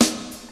• 1980s Rich Top End Jazz Snare One Shot A# Key 193.wav
Royality free steel snare drum tuned to the A# note. Loudest frequency: 2921Hz